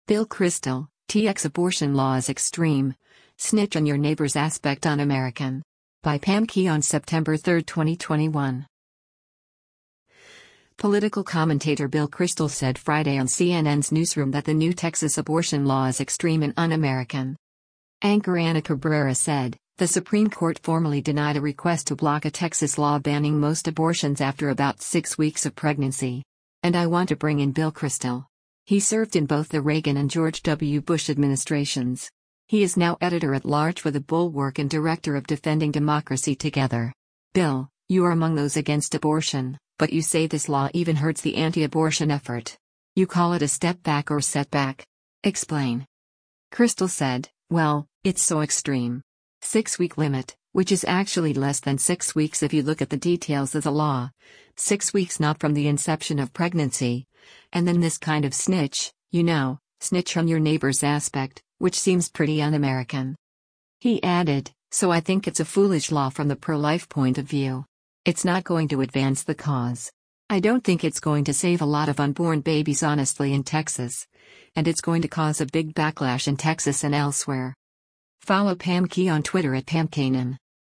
Political commentator Bill Kristol said Friday on CNN’s “Newsroom” that the new Texas abortion law is “extreme” and “un-American.”